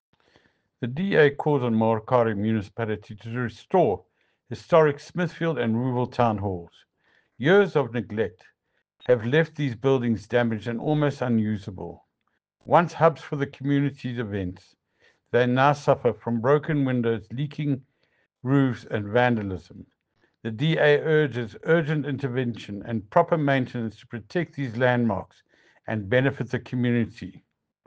English soundbite by Cllr Ian Riddle,